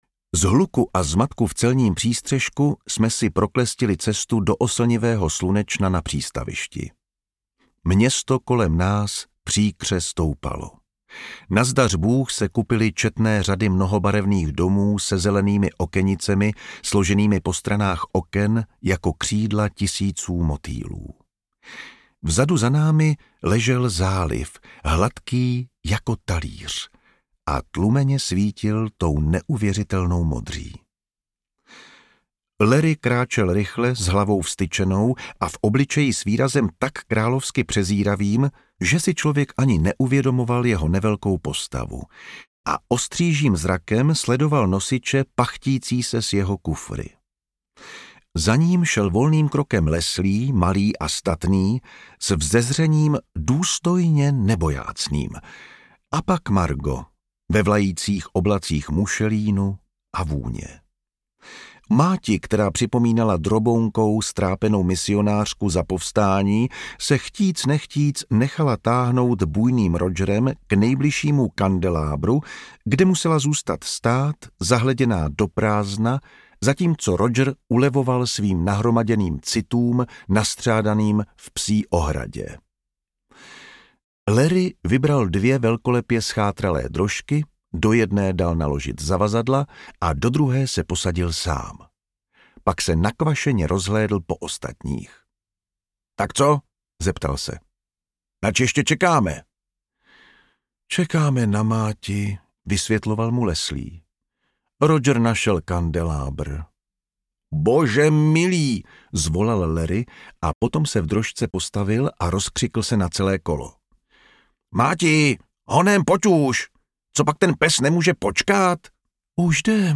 O mé rodině a jiné zvířeně audiokniha
Ukázka z knihy
• InterpretJan Vondráček